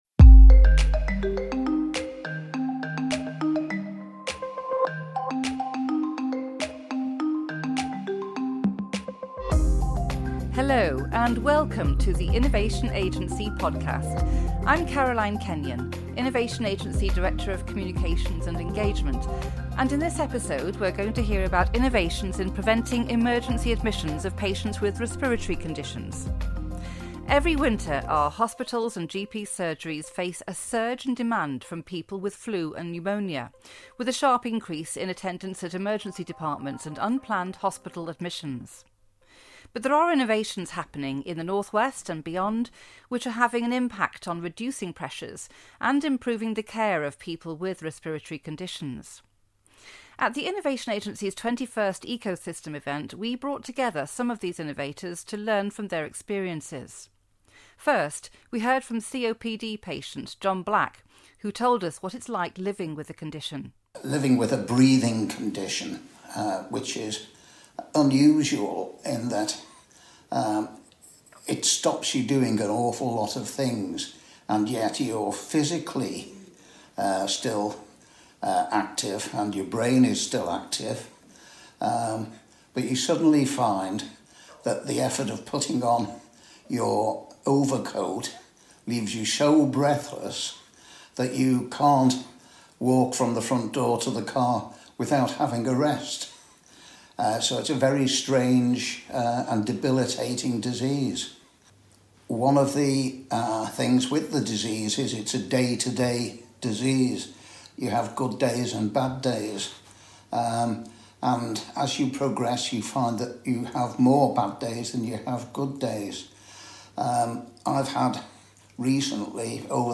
Innovators describe the changes they've made which have reduced unplanned admissions and emergencies for patients with long term respiratory conditions.